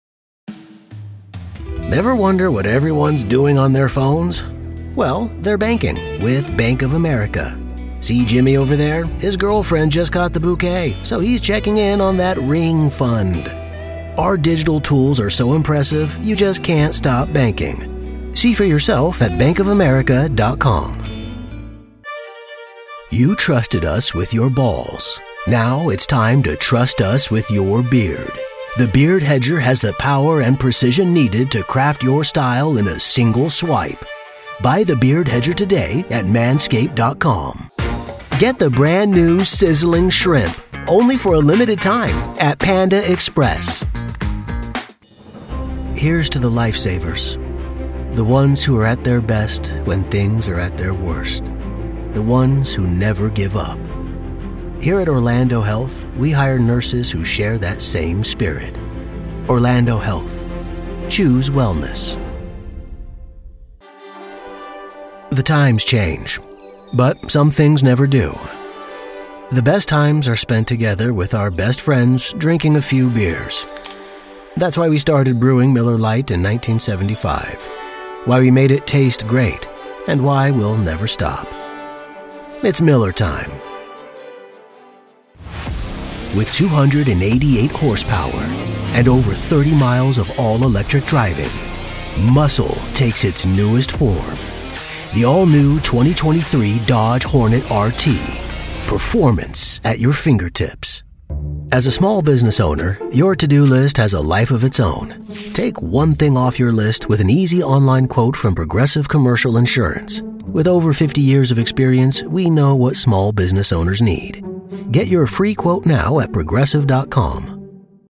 Commercial Demo
Middle Aged
Friendly | Conversational | Genuine
Warm | Trustworthy | Authoritative